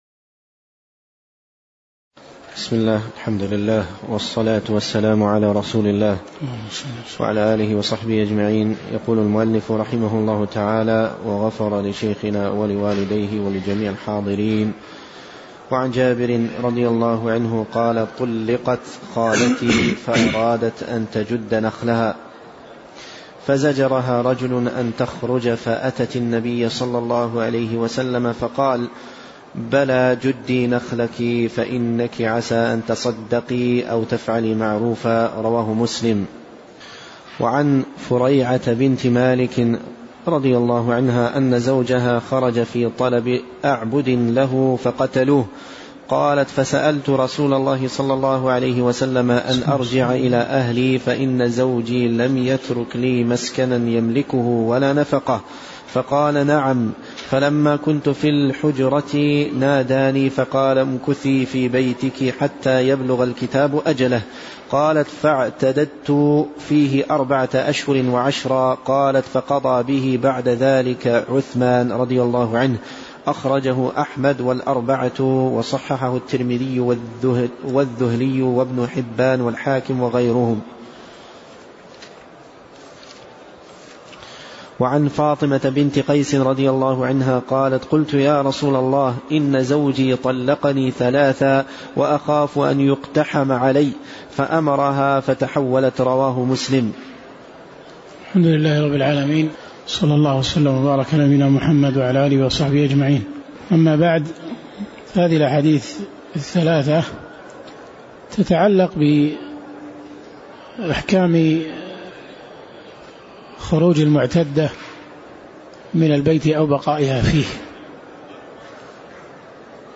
تاريخ النشر ٢٧ محرم ١٤٣٩ هـ المكان: المسجد النبوي الشيخ